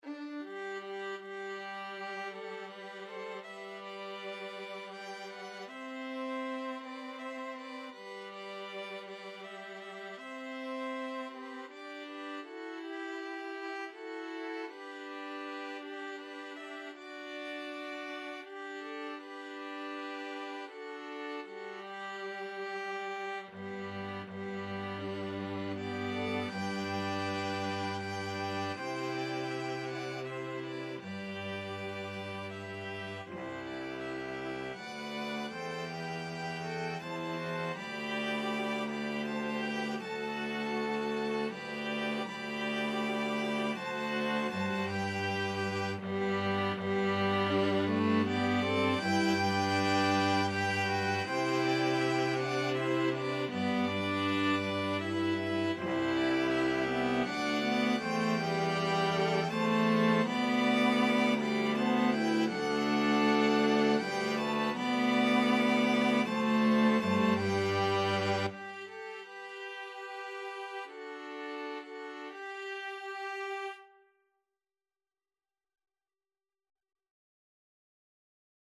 Violin 1Violin 2ViolaCello
"Oh Shenandoah" (also called simply "Shenandoah", or "Across the Wide Missouri") is a traditional American folk song of uncertain origin, dating at least to the early 19th century.
G major (Sounding Pitch) (View more G major Music for String Quartet )
3/4 (View more 3/4 Music)
Andante
String Quartet  (View more Easy String Quartet Music)
Traditional (View more Traditional String Quartet Music)